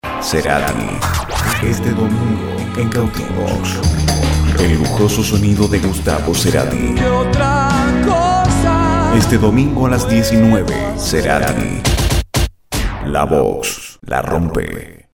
Voz en Off
Promo Gustavo Cerati - FM VOX (2004)